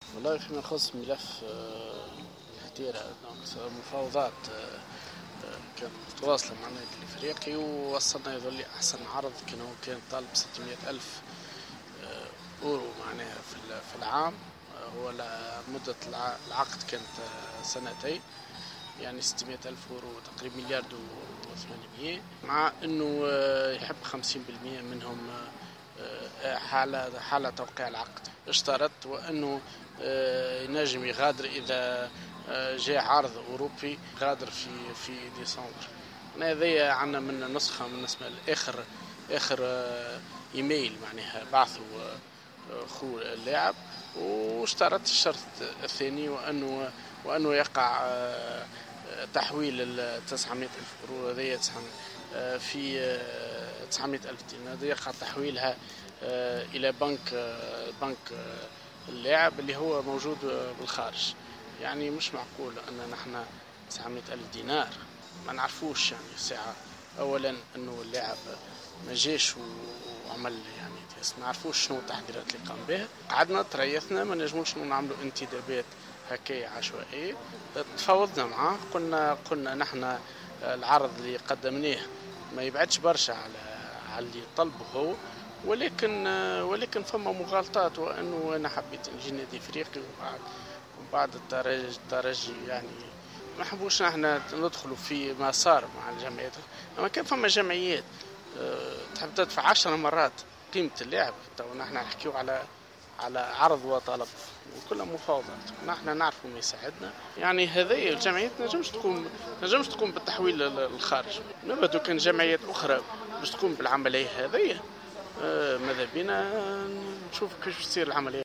حوار خاص